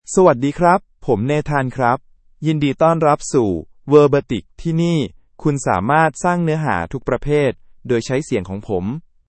Nathan — Male Thai AI voice
Nathan is a male AI voice for Thai (Thailand).
Voice sample
Listen to Nathan's male Thai voice.
Male
Nathan delivers clear pronunciation with authentic Thailand Thai intonation, making your content sound professionally produced.